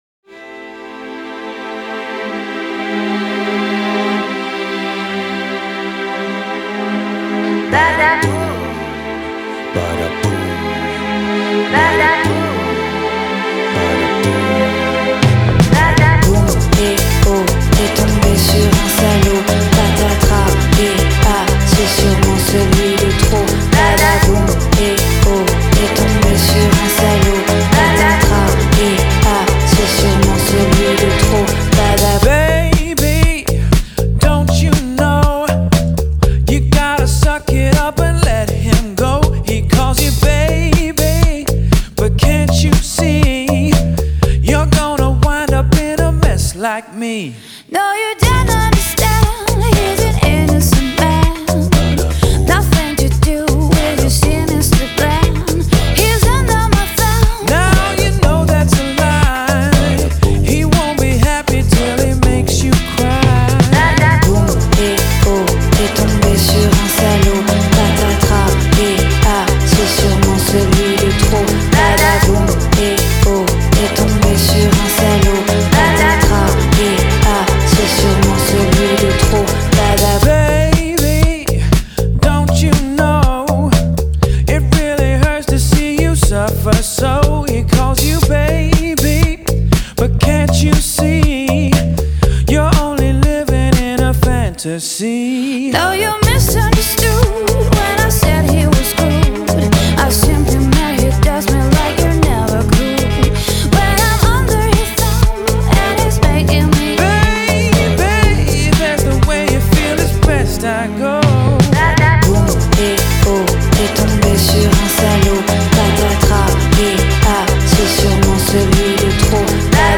Genre: Electronic, Alternative, Downtempo